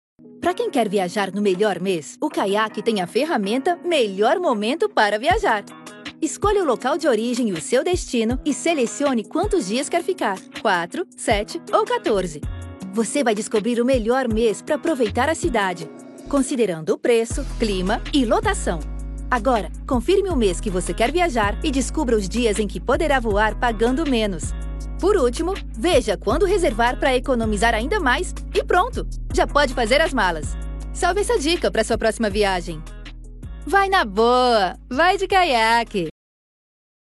Explainer Videos
My vocal range spans ages 13 to 40.
HighMezzo-Soprano